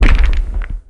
hurt.ogg